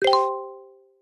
match-confirm.wav